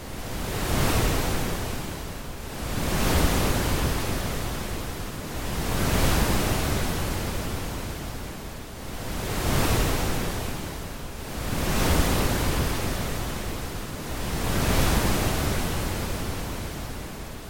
声景 " 海景
描述：正如潮水达到最高点时，在Pembrokeshire海滩上的Tascam DR07 Mkii上录制。海洋，泡沫和鹅卵石的未改变的声音。
标签： 沙滩 海岸 海浪 海洋 冲浪
声道立体声